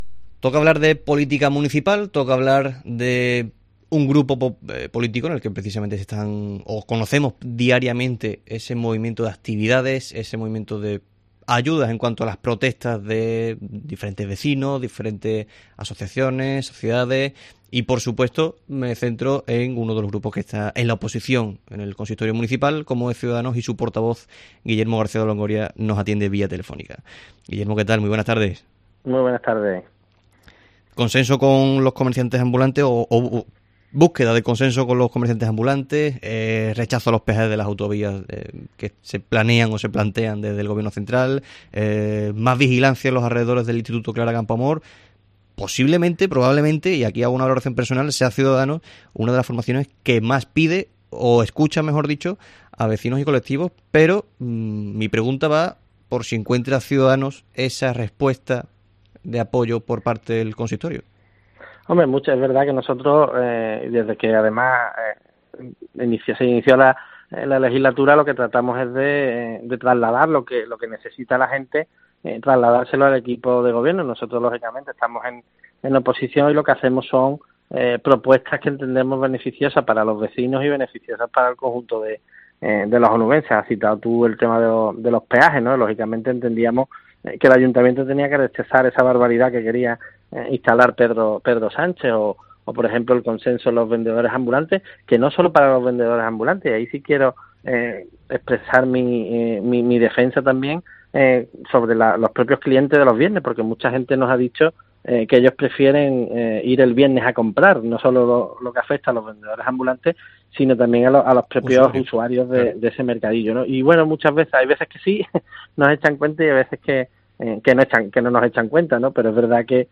Guillermo García de Longoria, portavoz de Cs en el Ayuntamiento de Huelva